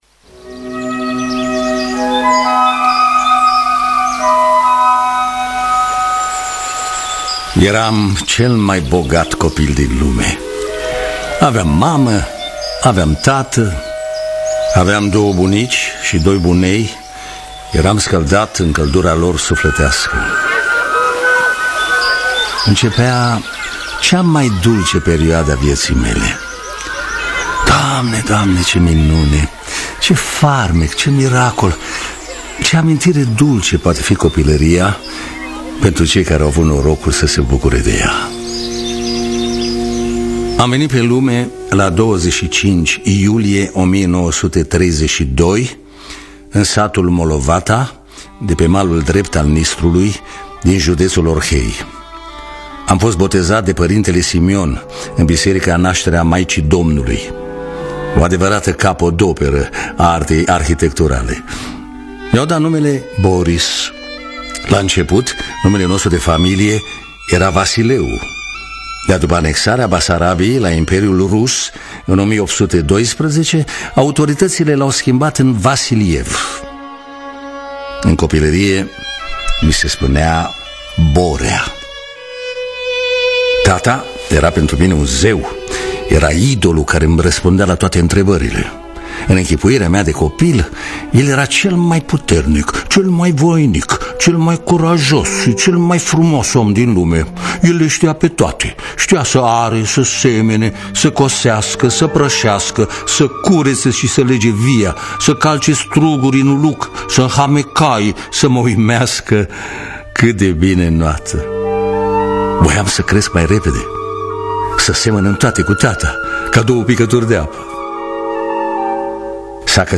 Biografii, Memorii: Boris Vasiliev – Stalin Mi-a Furat Copilaria (2019) – Teatru Radiofonic Online